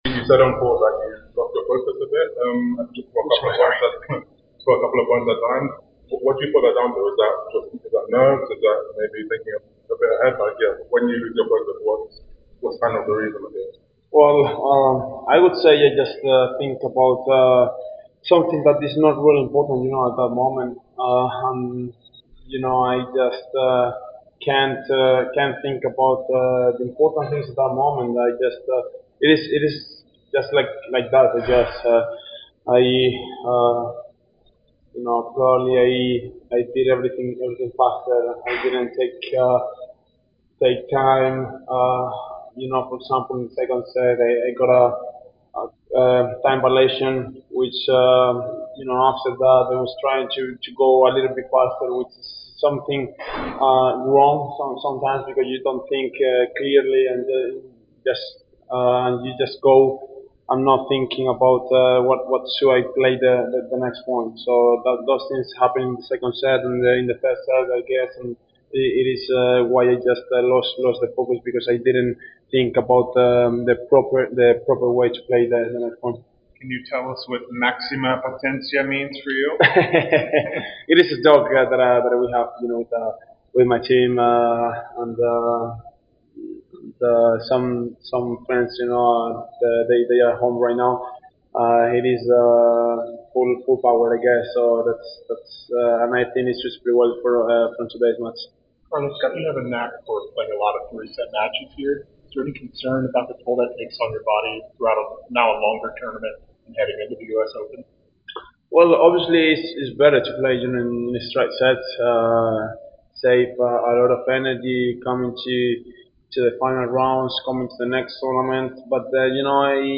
Carlos Alcaraz post-match interview after defeating Andrey Rublev 6-3. 4-6, 7-5 in the Quarterfinals of the Cincinnati Open.